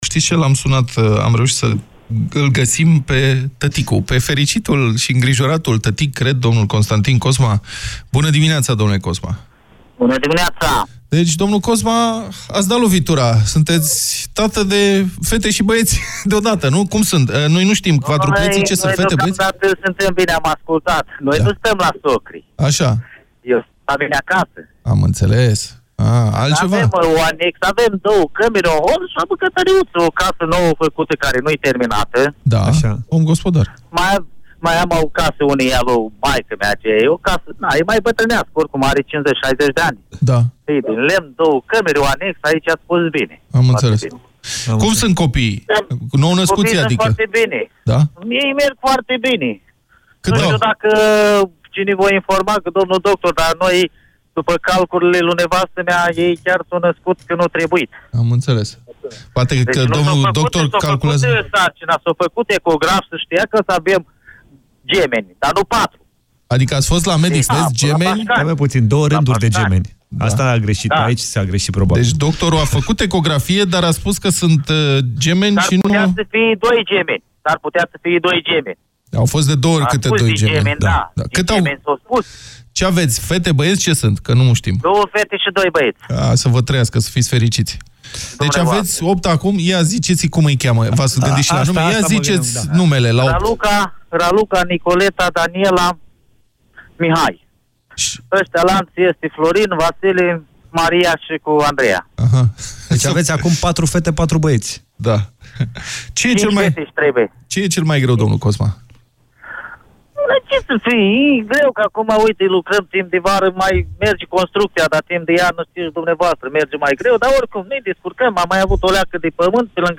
telefon-iasi.mp3